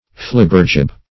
Search Result for " flibbergib" : The Collaborative International Dictionary of English v.0.48: Flibbergib \Flib"ber*gib\, n. A sycophant.